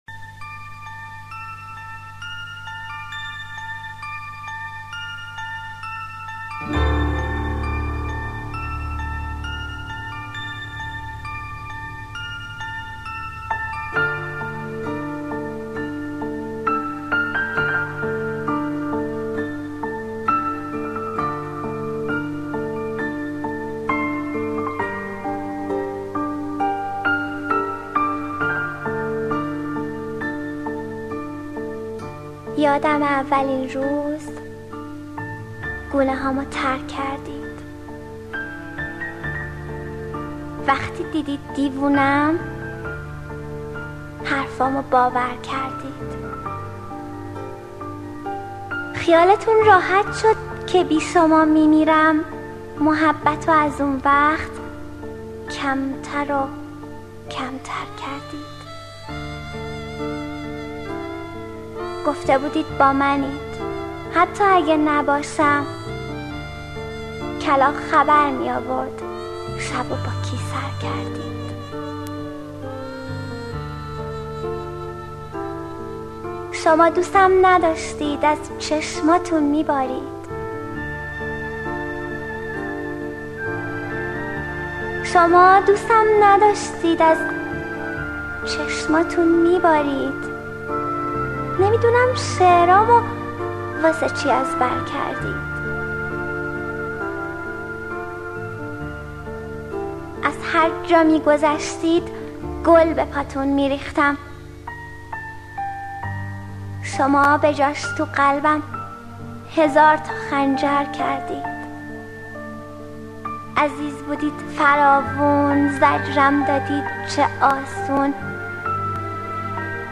دانلود دکلمه یادمه با صدای مریم حیدرزاده با متن دکلمه
اطلاعات دکلمه
گوینده :   [مریم حیدرزاده]